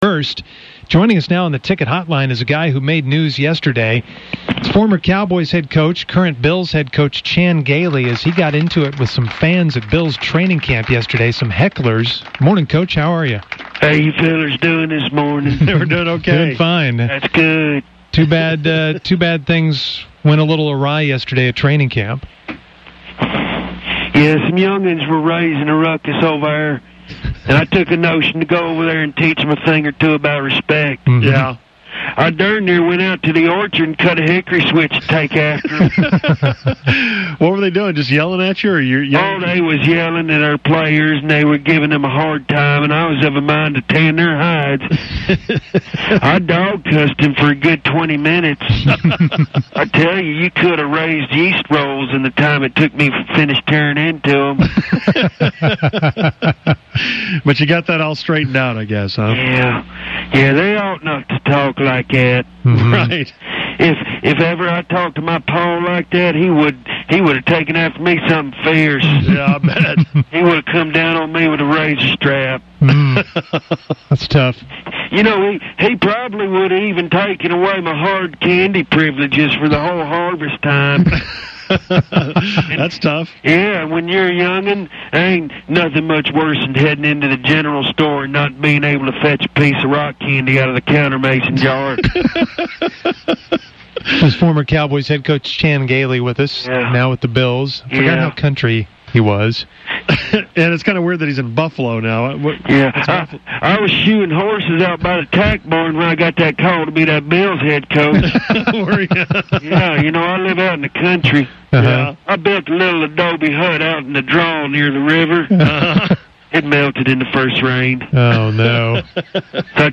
Of course, fake Chan Gailey really wanted to talk about his old boy country life. Musers dance around and laugh at his back-woods questions and answers.